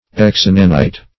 Search Result for " exinanite" : The Collaborative International Dictionary of English v.0.48: Exinanite \Ex*in"a*nite\, v. t. [L. exinanitus, p. p. of exinanire; ex out (intens.)